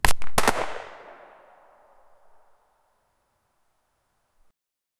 a-single-shot-from-a-bajukv5t.wav